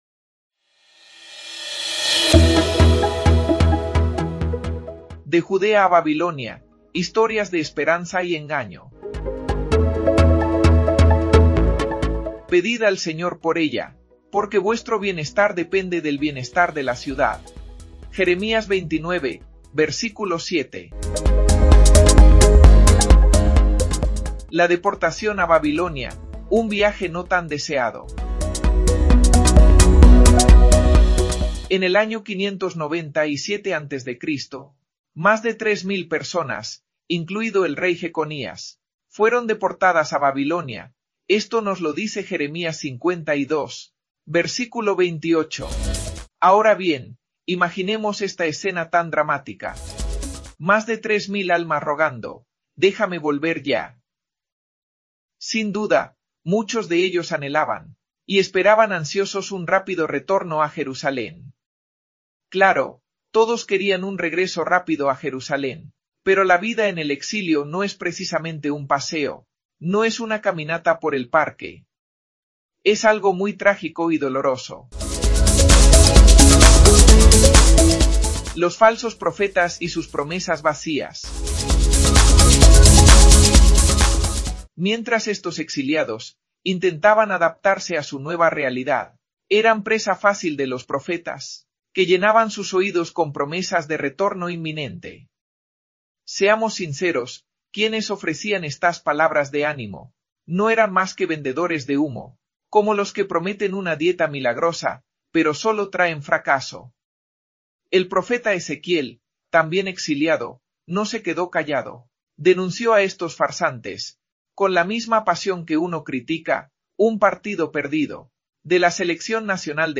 Jeremias-29-audio-con-musica.mp3